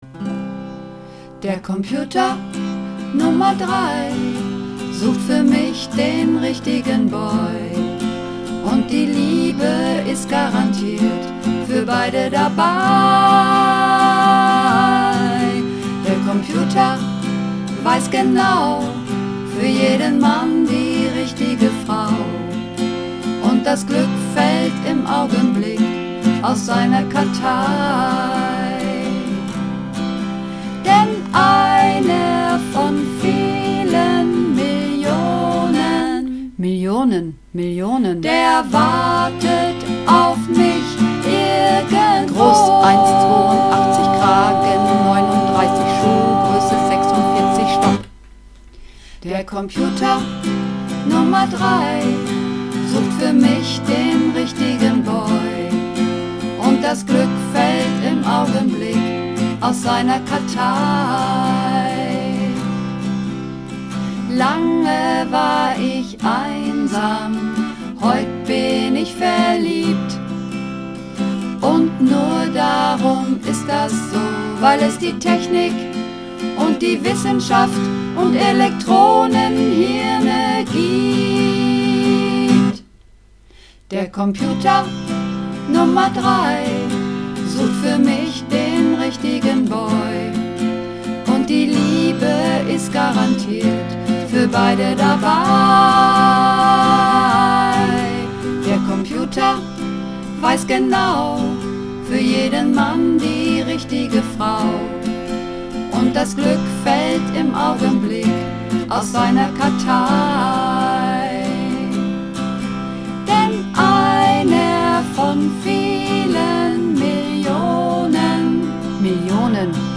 Übungsaufnahmen - Der Computer Nr. 3
Runterladen (Mit rechter Maustaste anklicken, Menübefehl auswählen)   Der Computer Nr. 3 (Mehrstimmig)
Der_Computer_Nr_3__4_Mehrstimmig.mp3